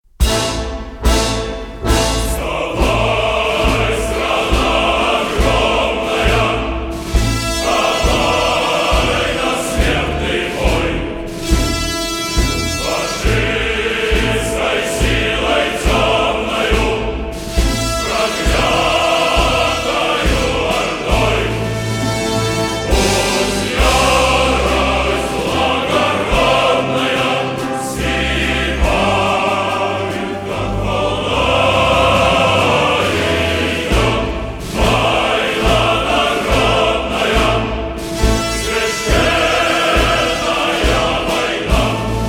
Рингтоны Военные